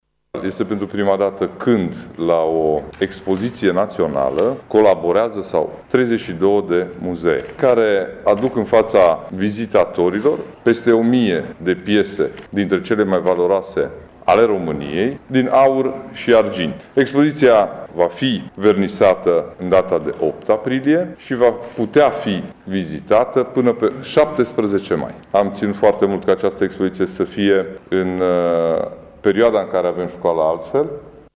Președintele CJ Mureș, Ciprian Dobre, a arătat că e bine că evenimentul are loc pe perioada ”Școlii altfel”, astfel elevii vor putea admira acest tezaur deosebit: